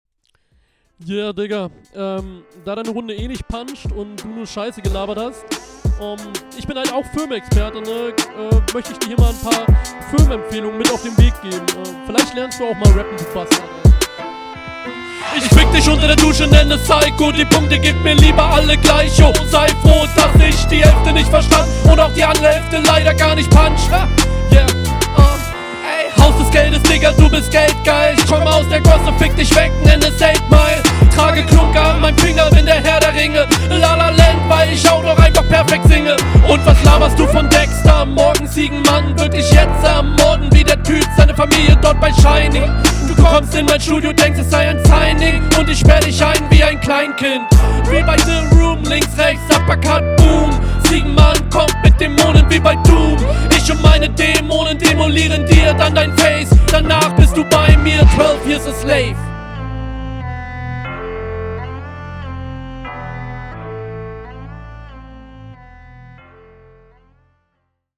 Der Stimmeinsatz ist gerade im Vergleich sehr schön aggressiv, es werden mit Betonungen einzelne Sachen …
Flow: Stabil ist es geflowt und er ist auch schön Aggro stimmlich Text: Oh GOTT …
Flow: Der Einstieg hat mich zum linksdreh des Lautstärkereglers bewegt, ballert auf jeden. Flow ist …